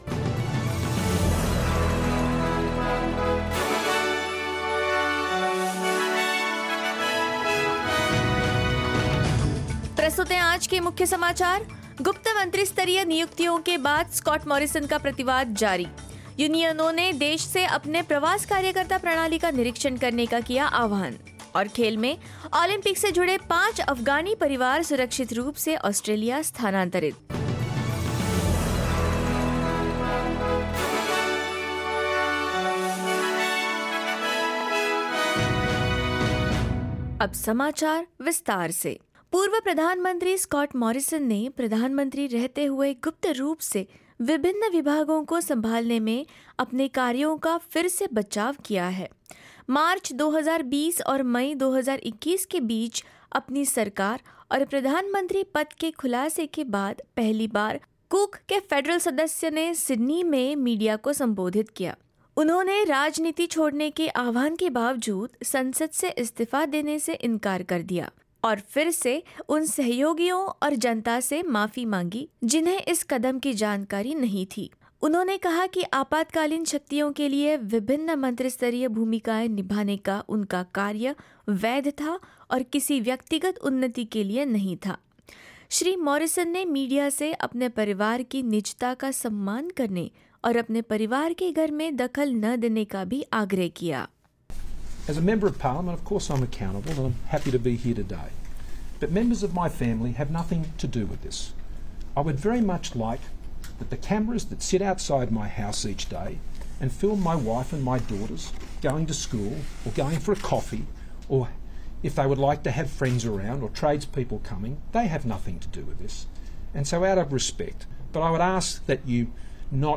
hindi-news-1708.mp3